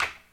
TC Clap Perc 08.wav